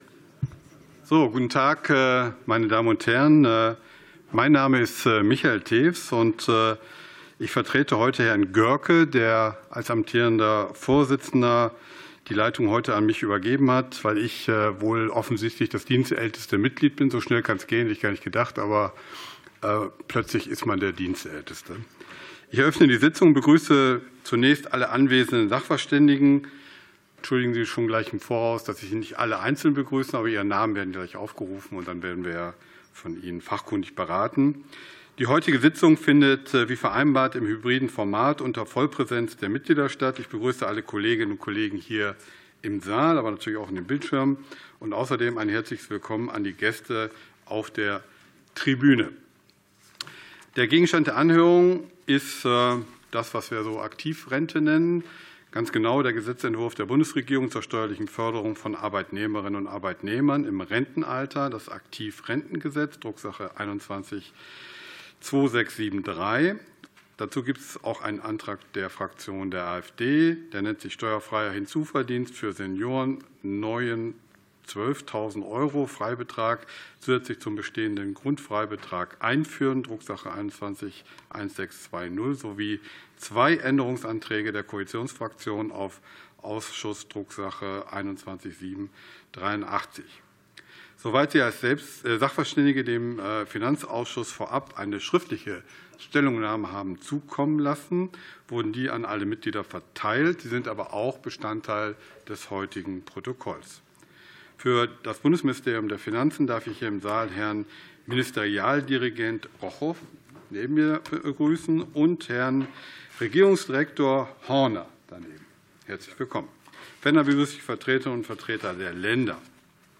Anhörung des Finanzausschusses